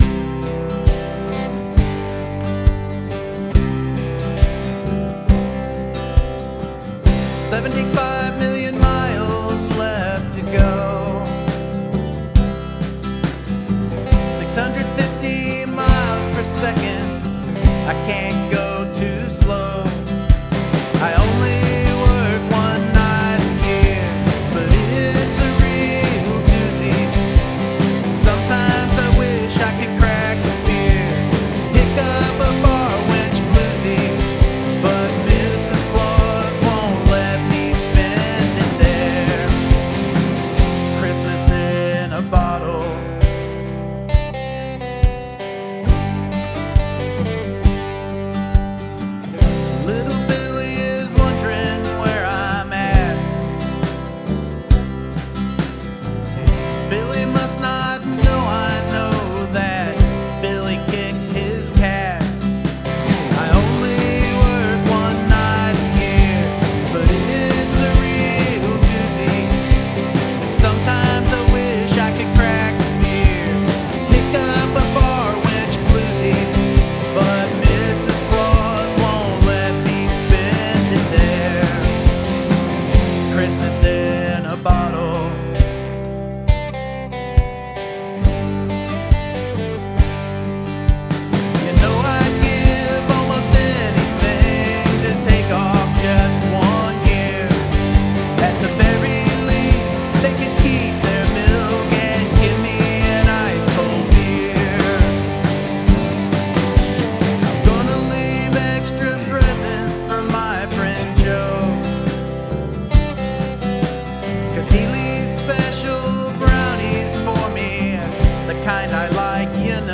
I love the kick and bass on this...
Nice alternative kind of vibe with a touch of country.
I also dug the strummed acoustic. It was so full sounding.
Love your guitar tones.
Rockin song.